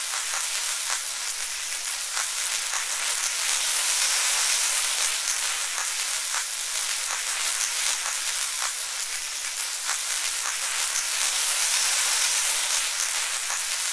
elec_lightning_magic_arc_loop3.wav